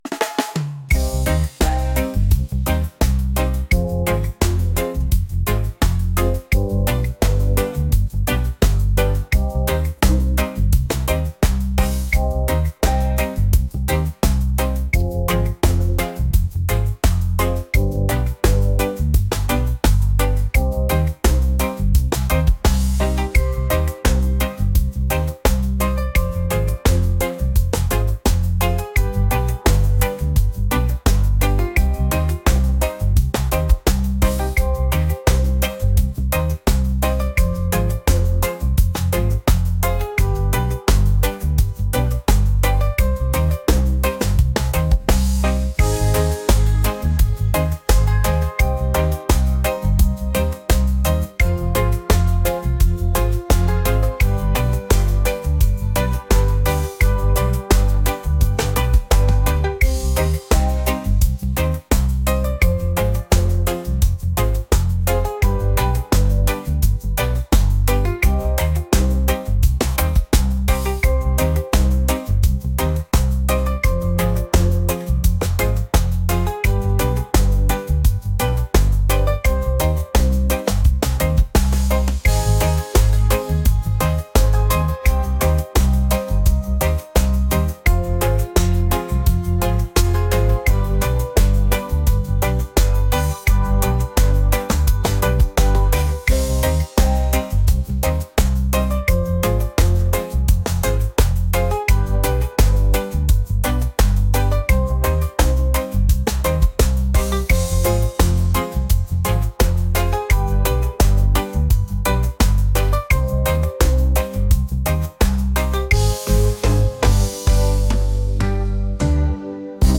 reggae | acoustic | soul & rnb